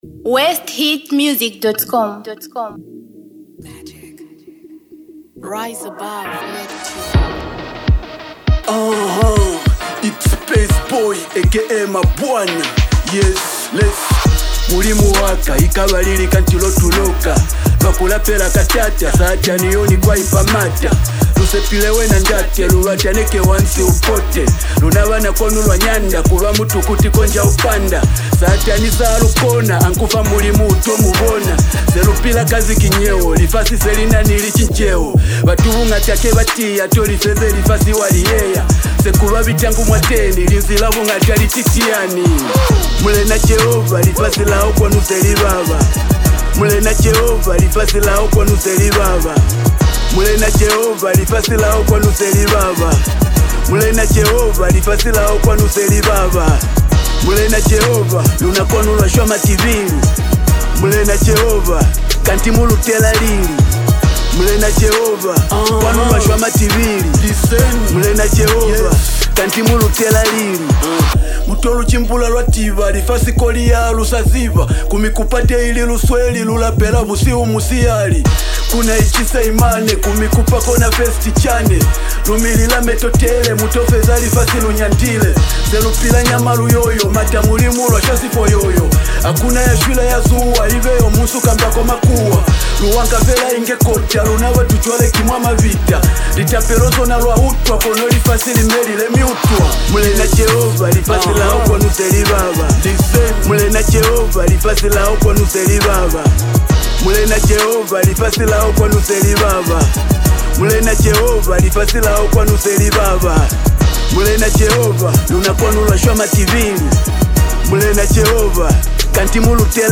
To enjoy this refreshing gospel sound